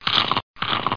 06142_Sound_Chew.mp3